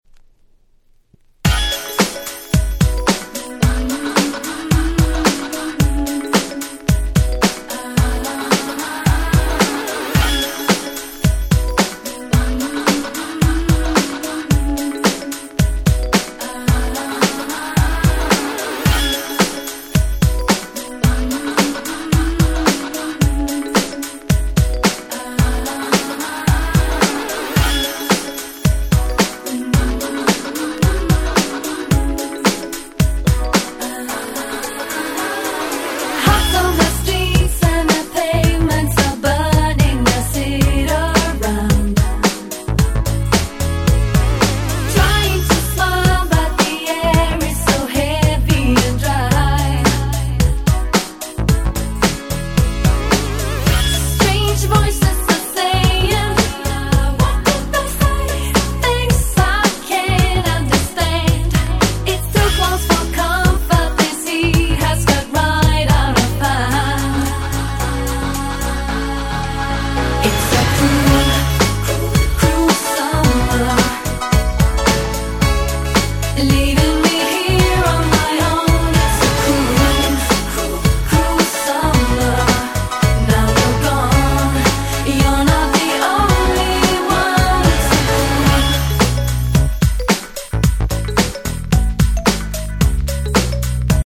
98' Nice Europe R&B !!